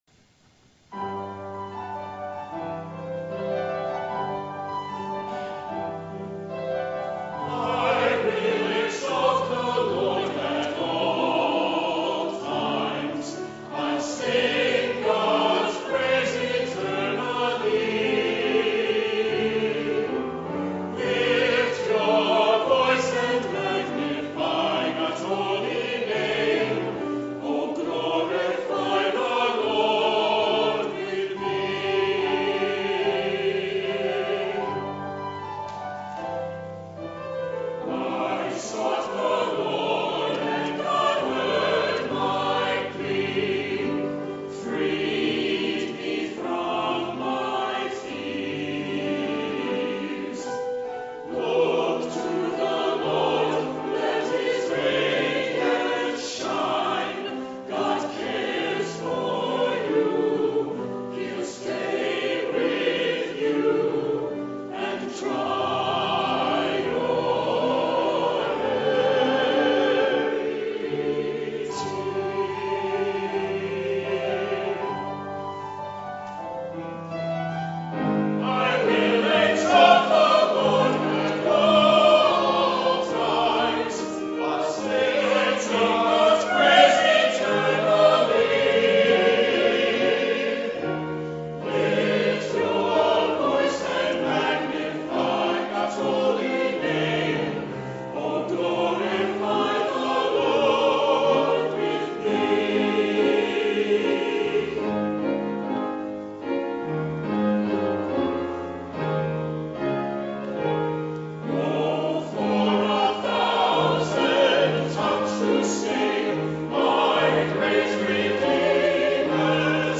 The Second Reformed Chancel Choir sings "I Will Exalt the Lord" by Lloyd Larson